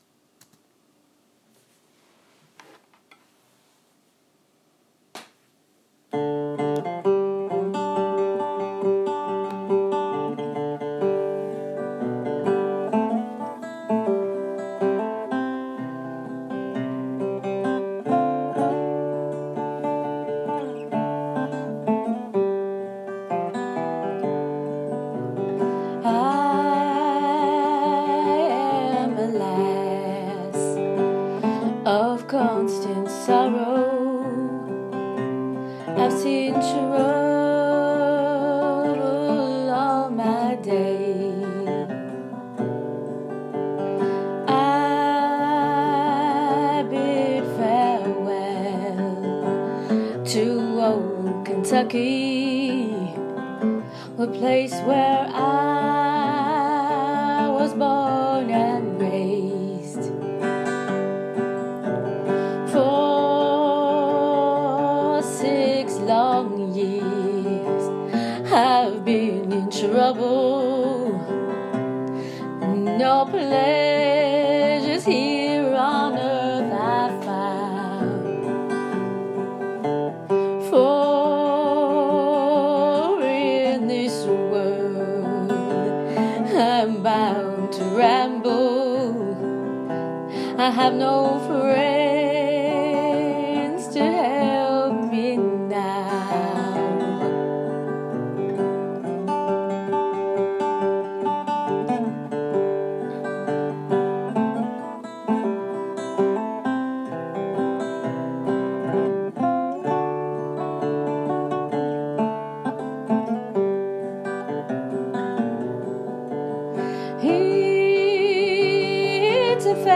Bluegrass is classic Americana, a rich part of our heritage.
I made it in one take, and it’s a departure from what you’re used to from me, but I thoroughly enjoyed the journey back to my days playing in bands.
A Lass of Constant Sorrow. Vocals
guitar by Norman Blake.
Your music was so good your voice really suits the Bluegrass..Enjoyed it very much.